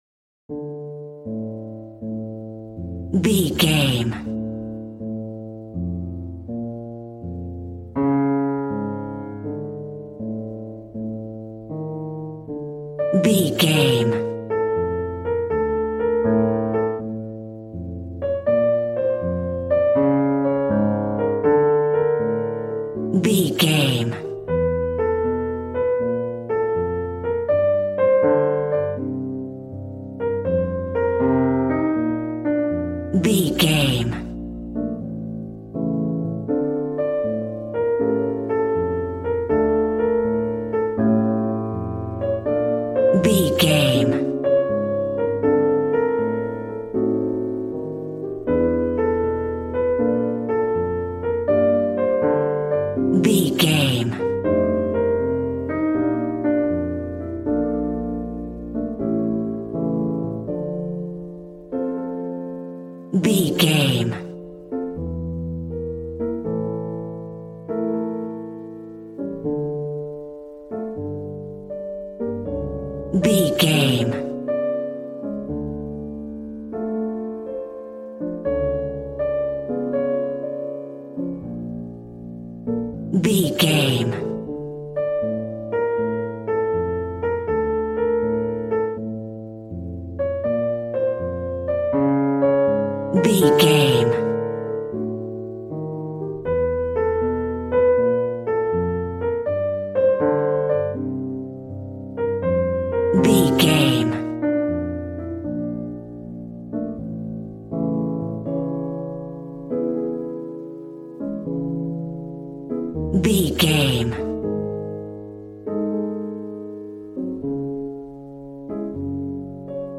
Aeolian/Minor
D
smooth
piano
drums